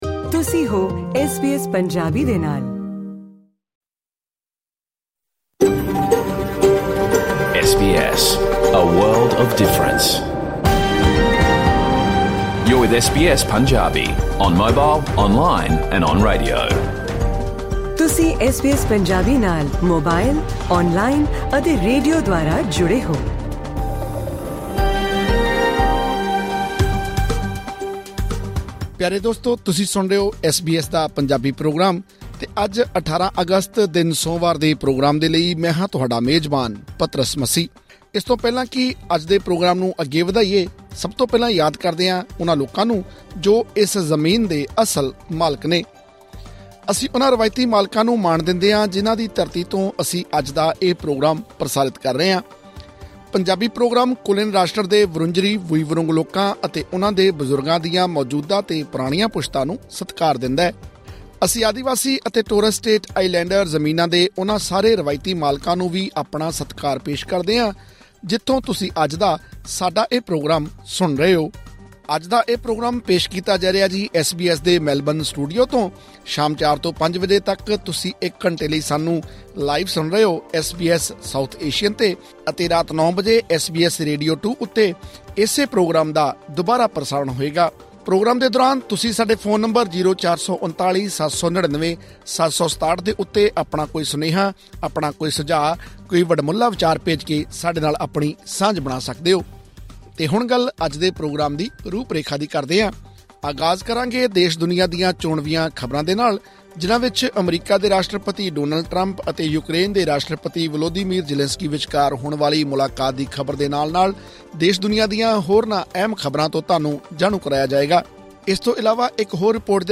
SBS Punjabi: Full Radio program